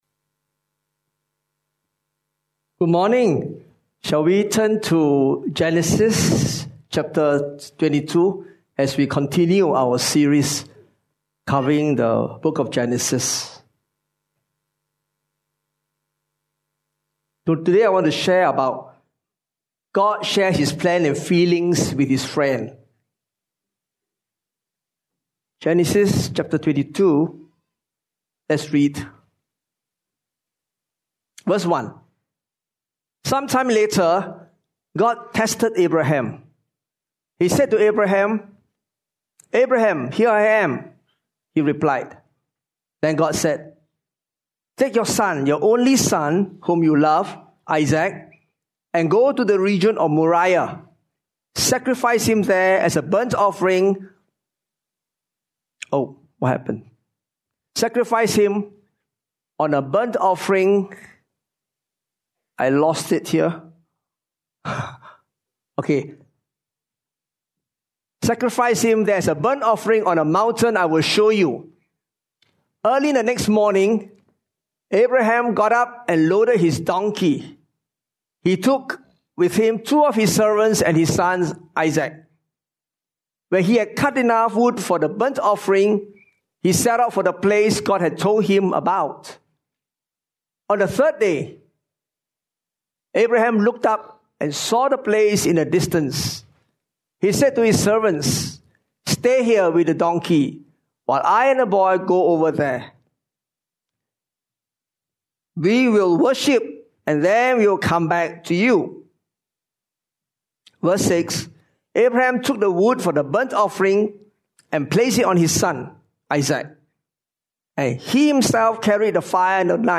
Otherwise just click, the video / audio sermon will just stream right to you without download.